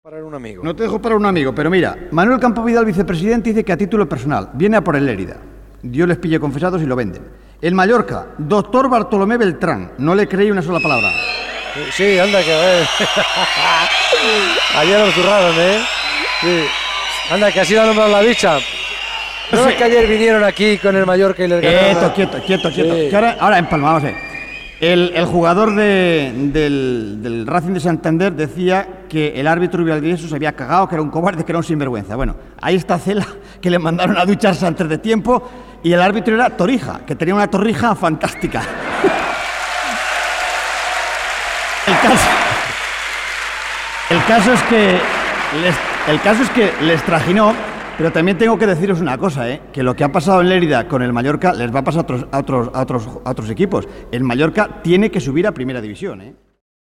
Esportiu
Programa fet cara al públic des de l'Auditori Enric Granados de Lleida.